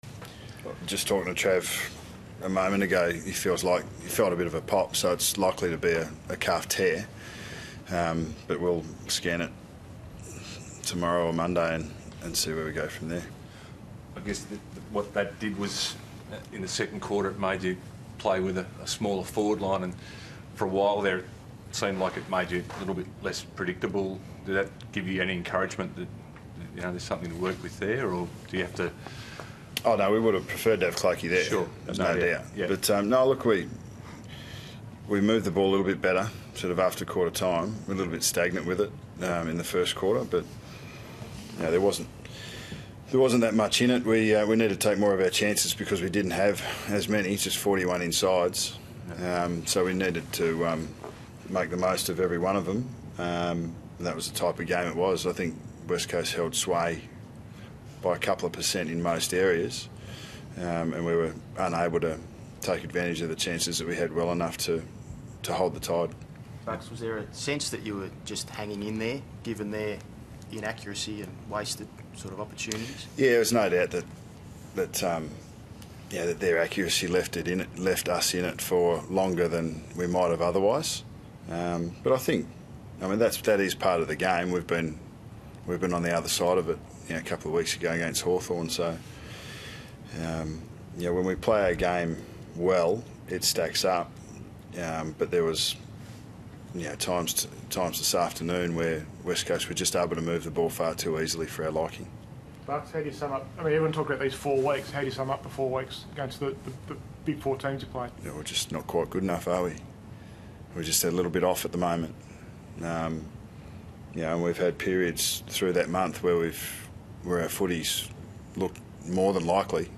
Listen to Nathan Buckley's post-match press conference following Collingwood's loss to West Coast in round 16, 2015.